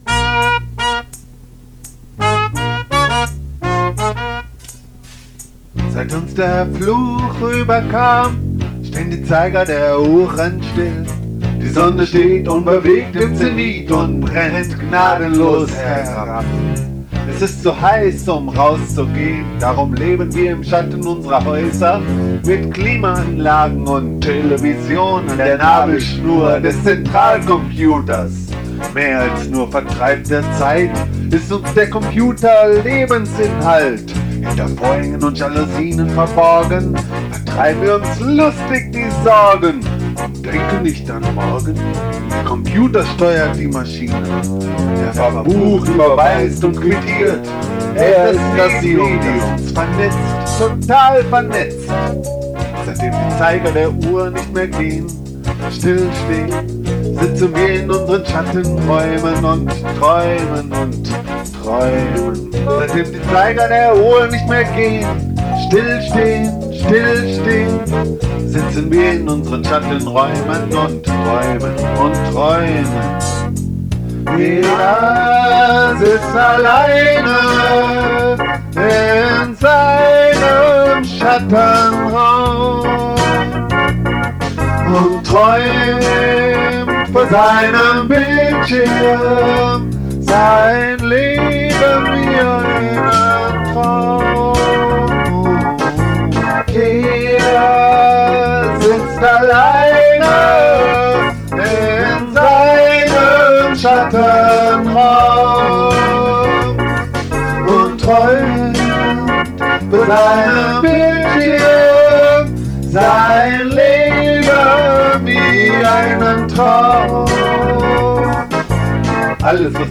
Gitarre und Vocal
Sologitarre
Bass
Drums